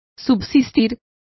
Complete with pronunciation of the translation of exist.